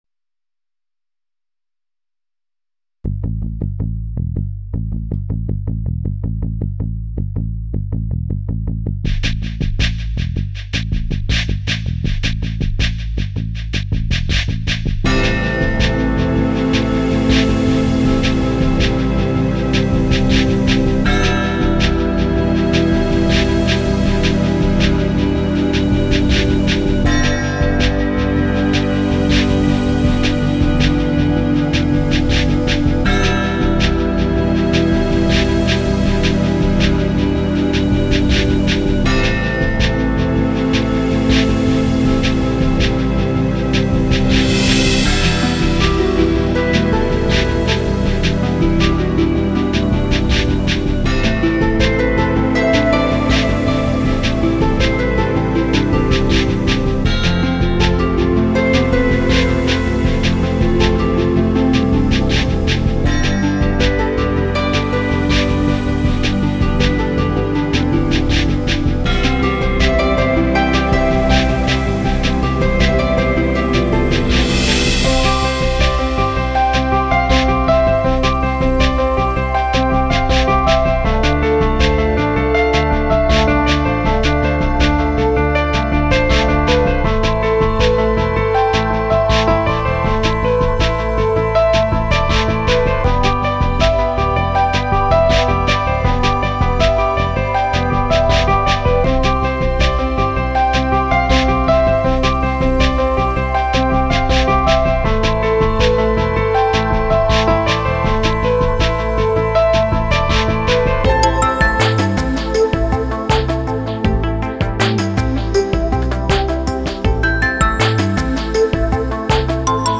ορχηστρικές συνθέσεις